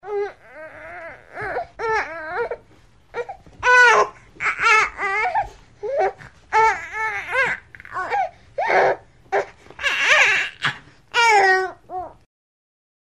Звук укачивания ребенка в сон